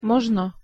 pronunciation_sk_mozno.mp3